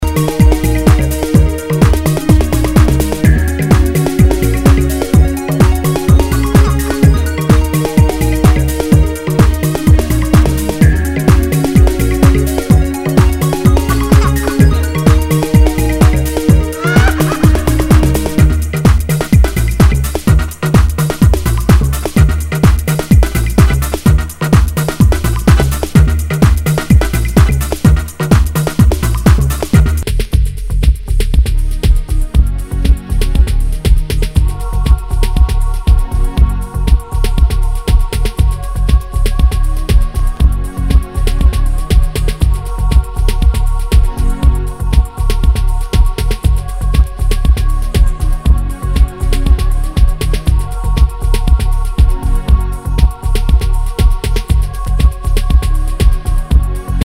HOUSE/TECHNO/ELECTRO